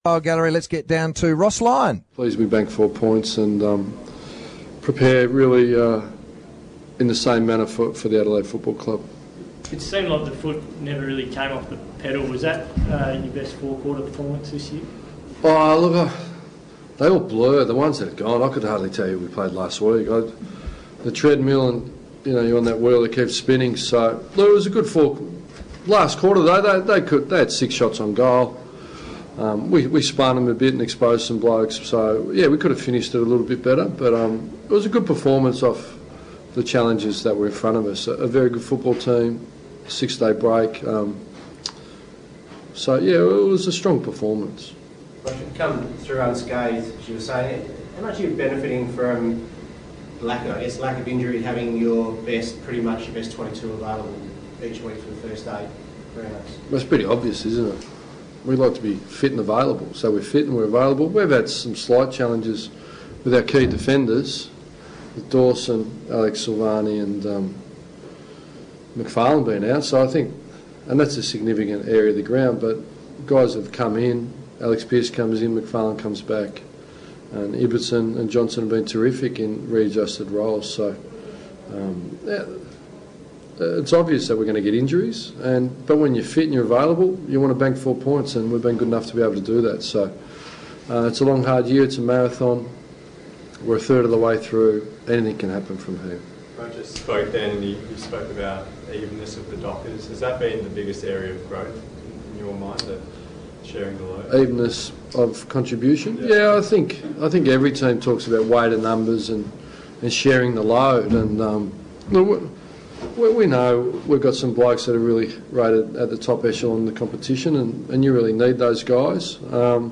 Ross Lyon's post game press conference
AFL Fremantle Dockers Coach Ross Lyon